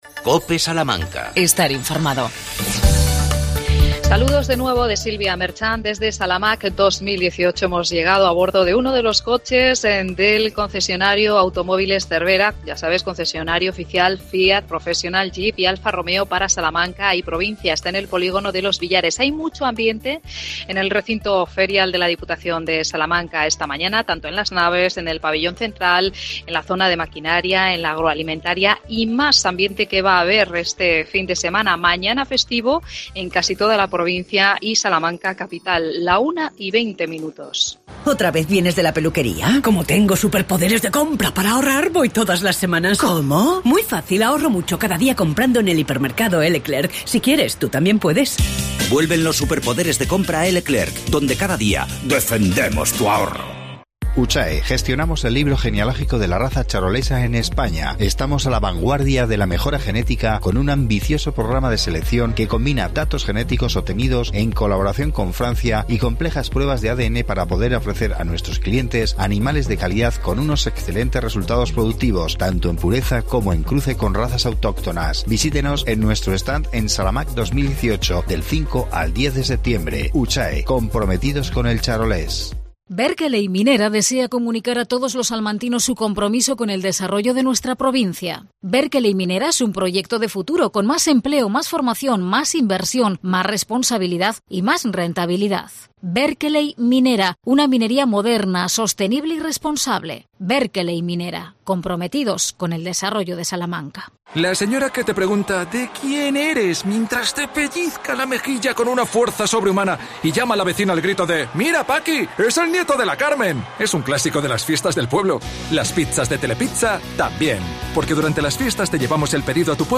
AUDIO: Programa especial desde Salamaq 2018